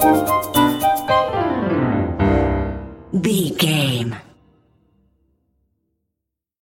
Aeolian/Minor
orchestra
piano
percussion
horns
silly
circus
goofy
comical
cheerful
Light hearted
sneaking around
quirky